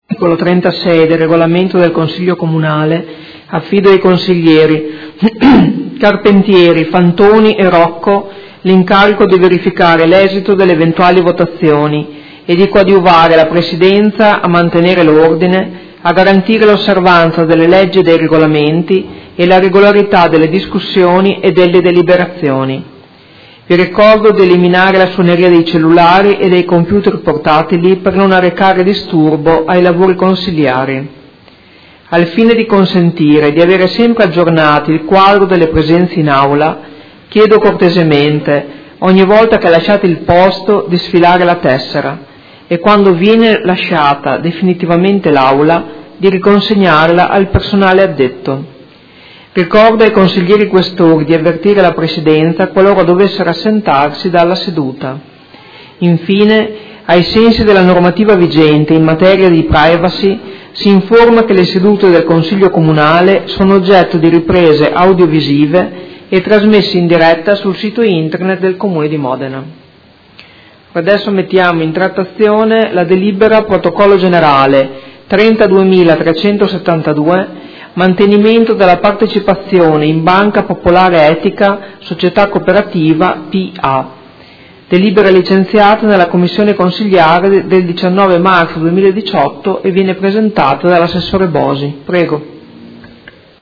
Presidentessa — Sito Audio Consiglio Comunale
Seduta del 26/03/2018 Apre i lavori del Consiglio Comunale.